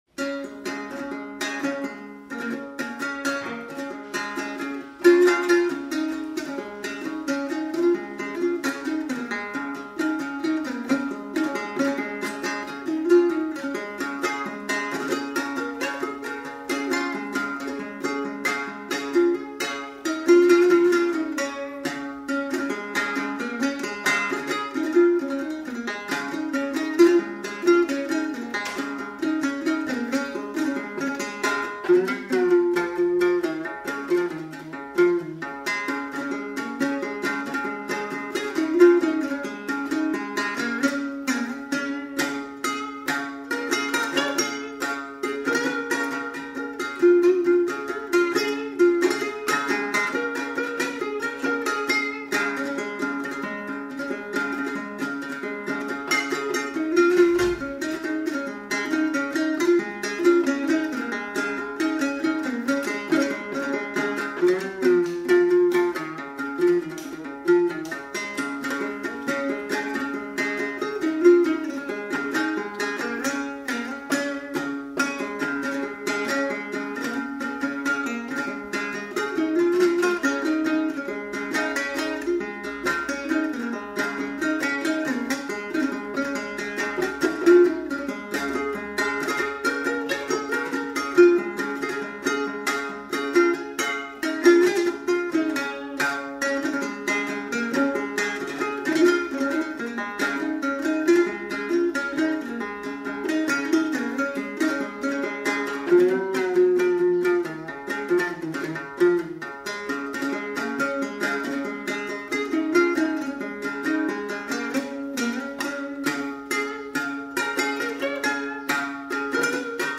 ◎制作群 ： 演出：月琴,壳仔弦｜
台湾民歌
18首原汁原味的素人歌声，在粗哑中满怀真性情，那是上一代的回忆，这一代的情感，下一代的宝藏！